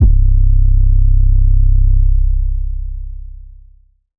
808s
808 - Boomin.wav